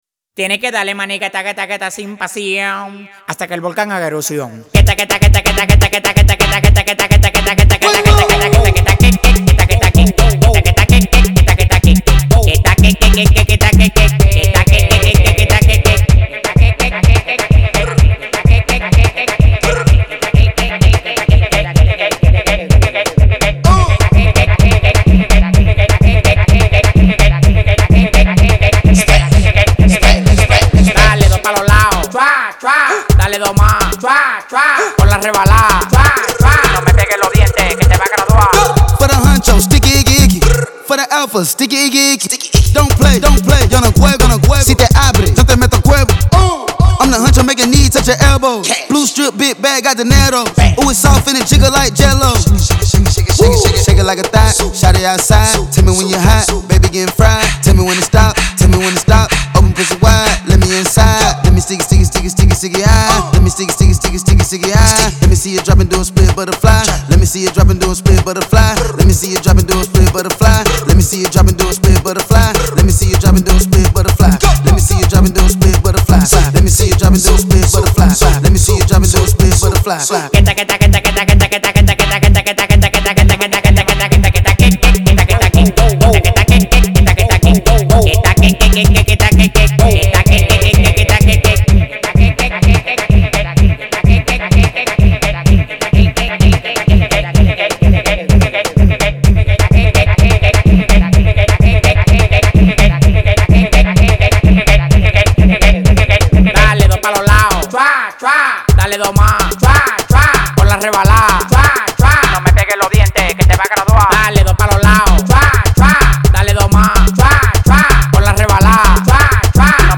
دانلود آهنگ سبک هیپ هاپ
Latin Music